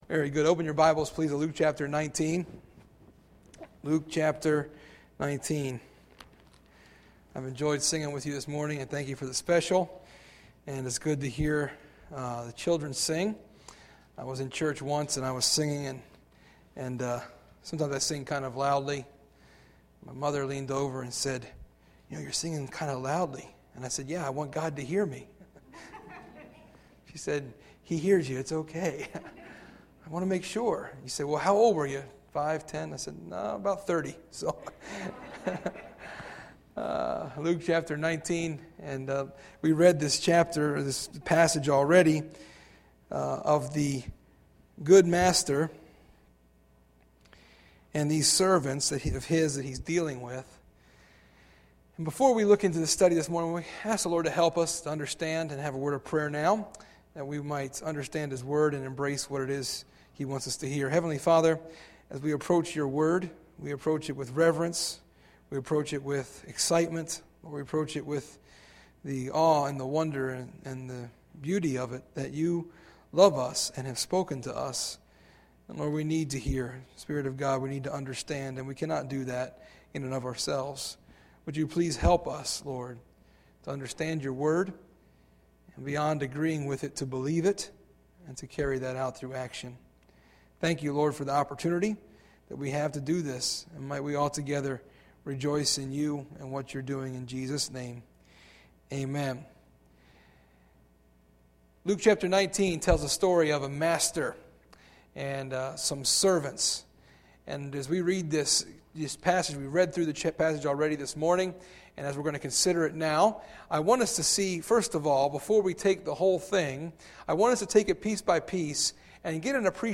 Sunday, August 28, 2011 – Morning Message